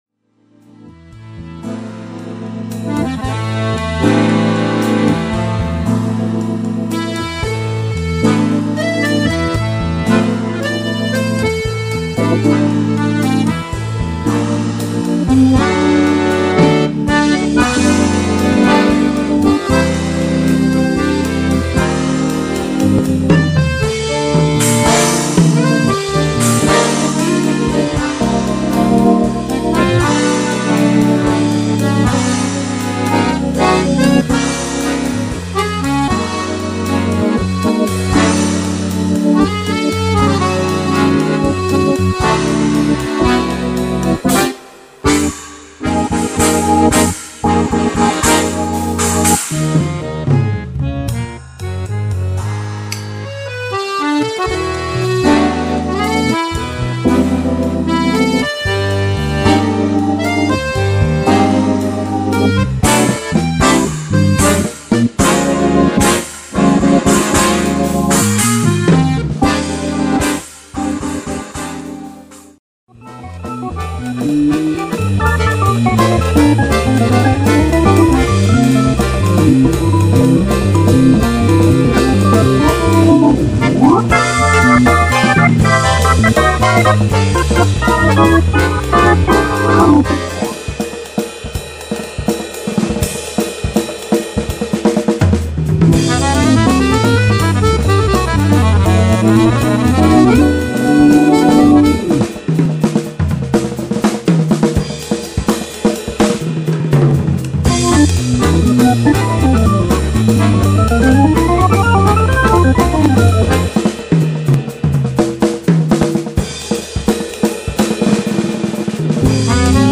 Une petite compilation des morceaux :